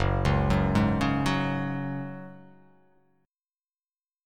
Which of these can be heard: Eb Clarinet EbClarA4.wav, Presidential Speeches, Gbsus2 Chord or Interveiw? Gbsus2 Chord